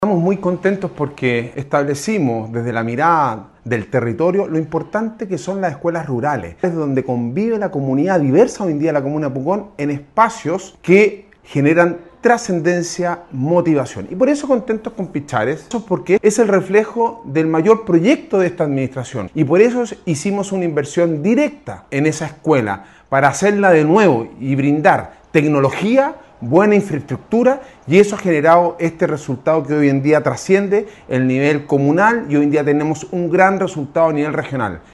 Alcalde-Sebastian-Alvarez-feliz-con-resultado-regional-de-Pichares-.mp3